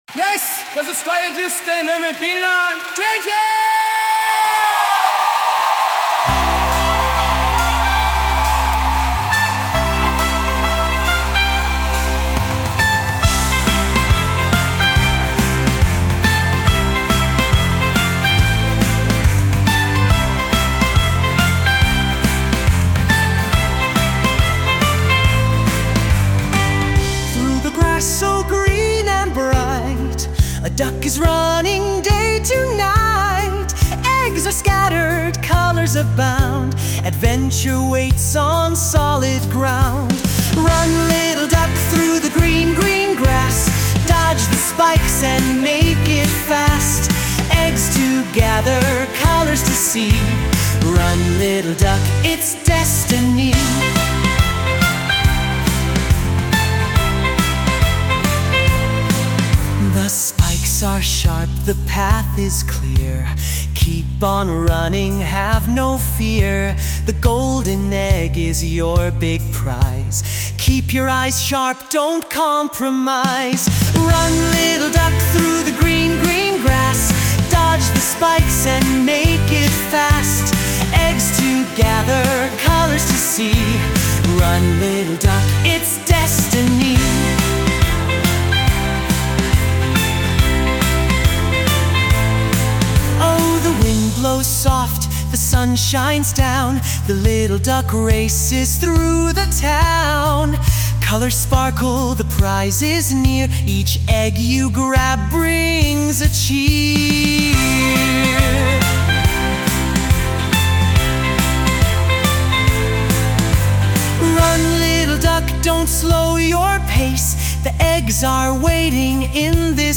Lyrics : Written by ChatGPT
Sung by Suno